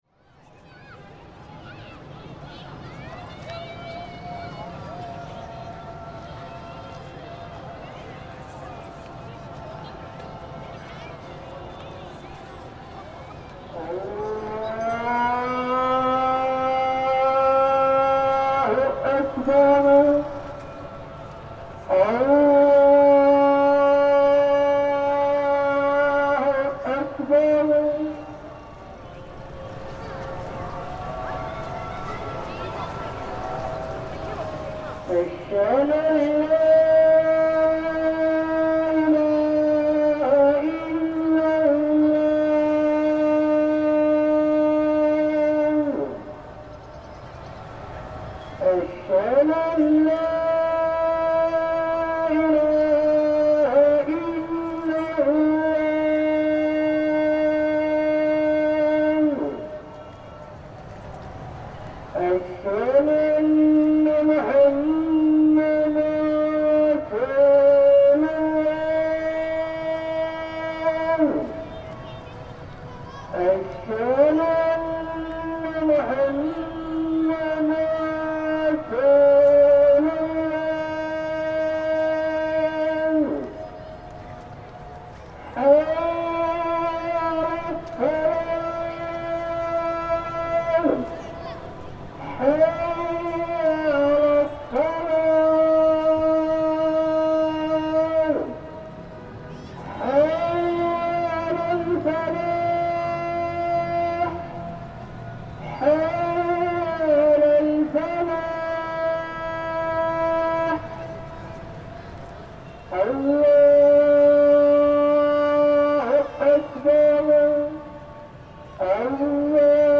Maghrib call to prayer, Koutoubia mosque
The maghrib call to prayer at 6.45pm from the biggest mosque in Marrakesh, the towering Koutoubia mosque.
Recorded in Marrakesh, Morocco, January 2026 by Cities and Memory.